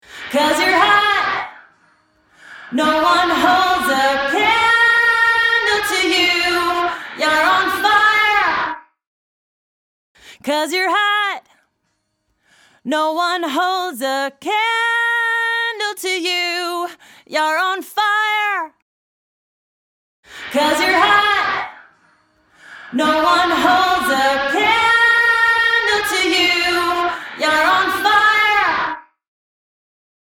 豊潤なリバーブと、引き裂くようなディストーション
MangledVerb | Vocals | Preset: IceMetal
MangledVerb-Vocals-Preset-IceMetal.mp3